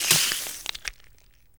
heal_success.wav